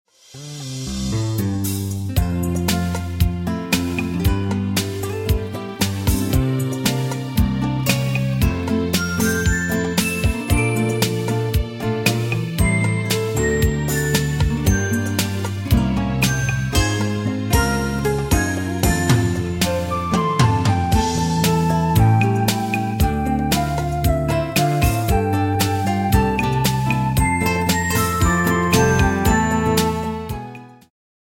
充滿動感和時代感
有伴奏音樂版本
標籤: 伴奏音樂